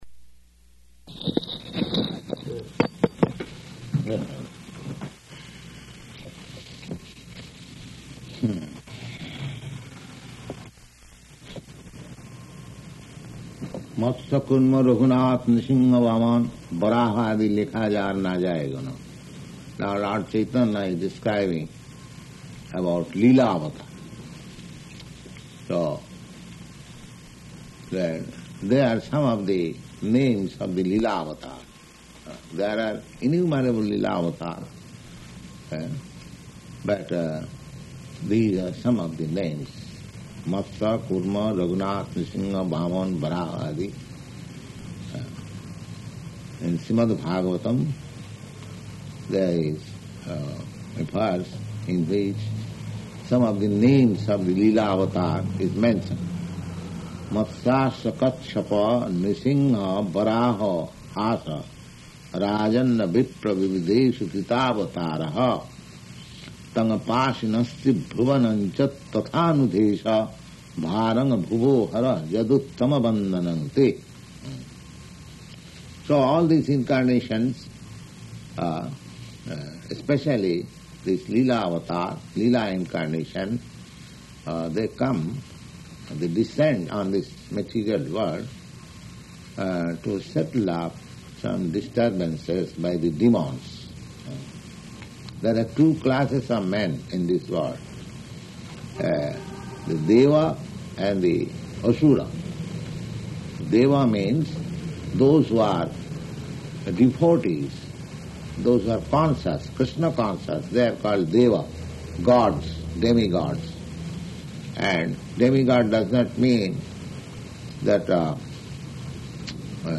Location: New York
[taps on microphone]